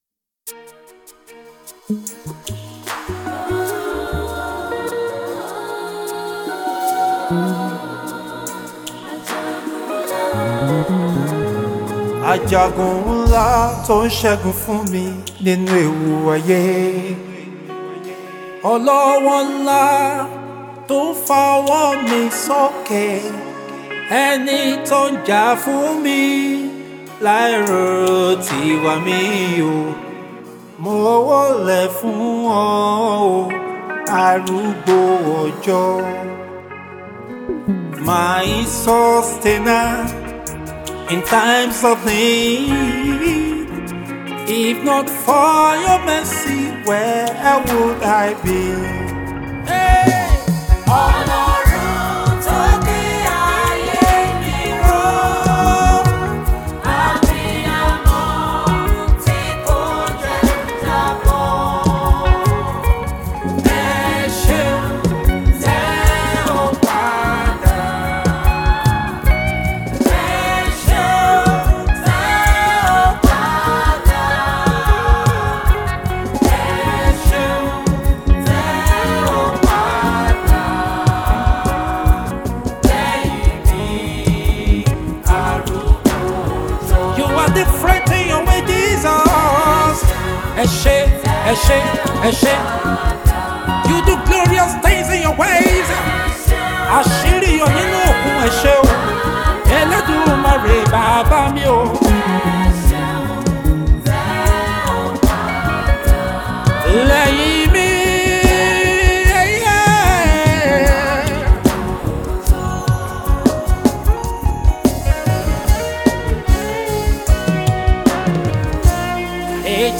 A Nigerian gospel artiste
is a soul lifting song , worship and appreciation to God.